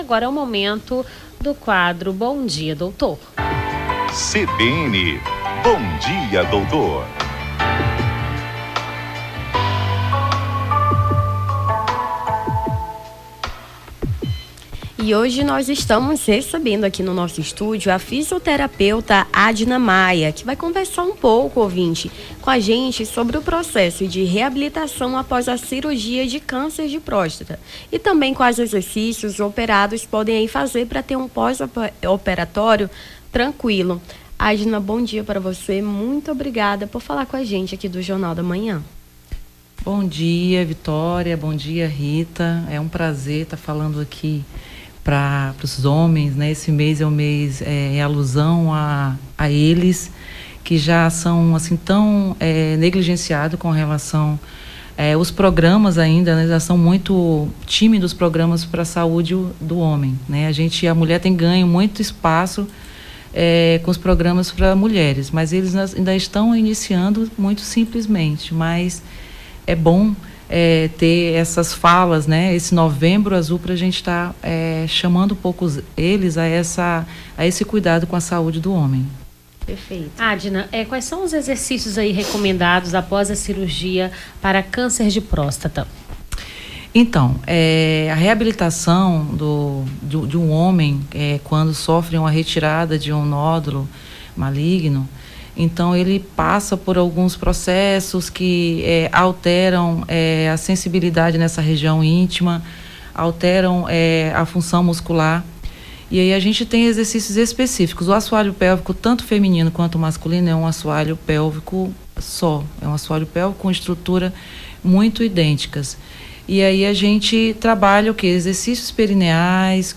AO VIVO: Confira a Programação